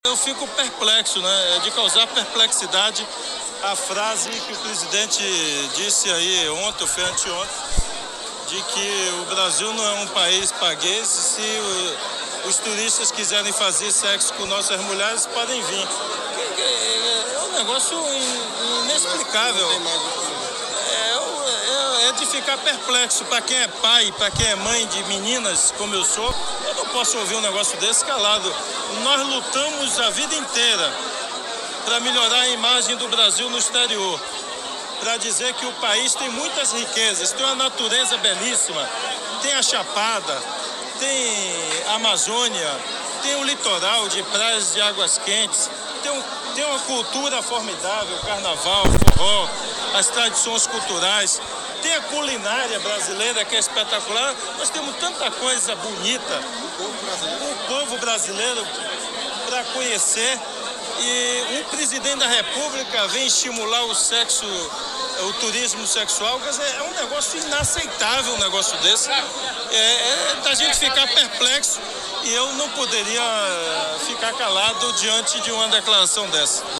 Ao descer do palanque ele enfatizou seu comentário para a imprens a